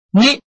拼音查詢：【饒平腔】nib ~請點選不同聲調拼音聽聽看!(例字漢字部分屬參考性質)